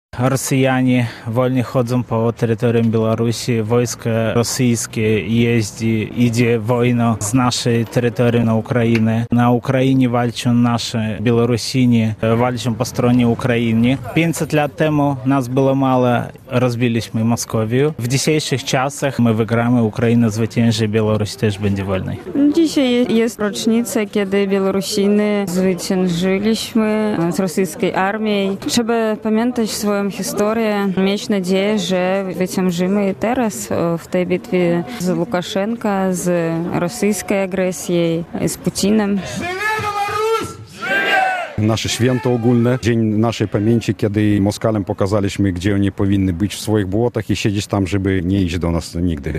relacja
W rocznicę tego historycznego wydarzenia Diaspora Białoruska w Białymstoku zorganizowała manifestację przed konsulatem Białorusi.